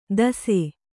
♪ dase